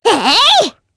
Sonia-Vox_Attack4_jp.wav